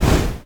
poly_explosion_fireball2.wav